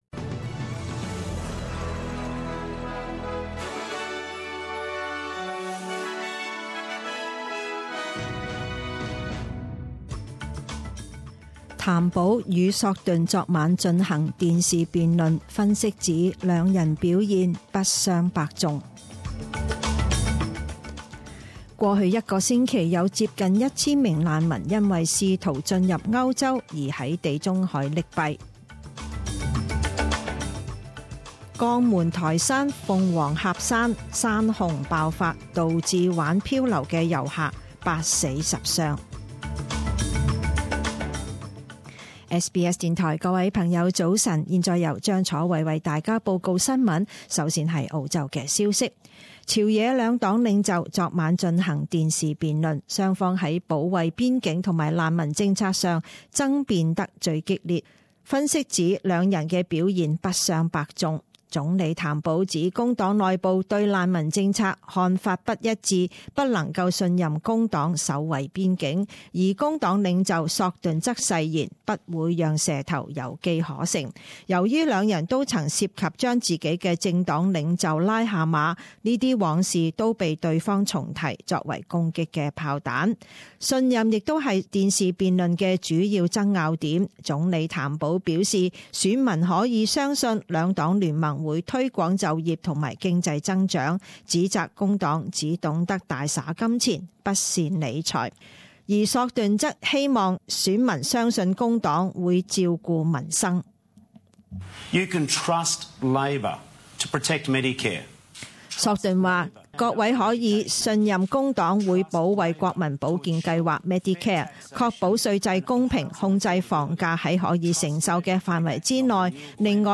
五月三十日十點鐘新聞報導